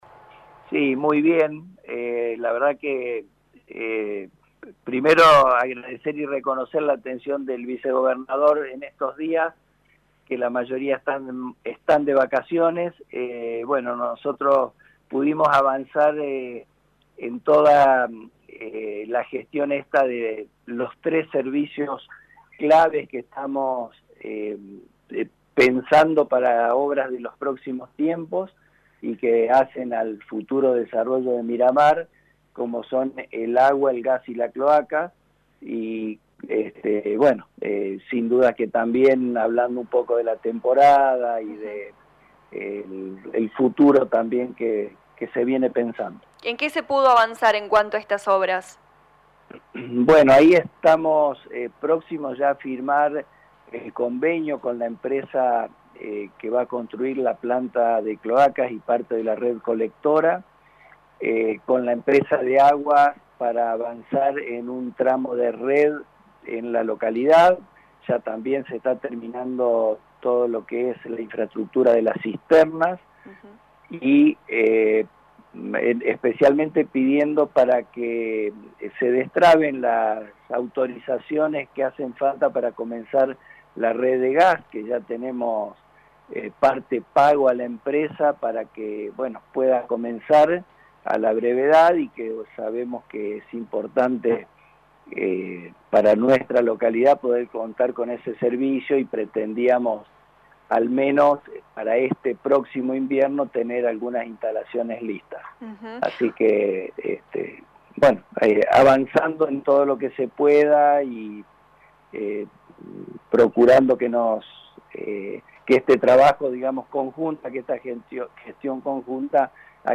En diálogo con LA RADIO 102.9 FM el intendente de Miramar de Ansenuza Adrián Walker informó que esta semana estuvo reunido en Córdoba junto al vicegobernador Cr. Manuel Calvo para tratar diversos temas de gestión que incumben a esa localidad del departamento San Justo.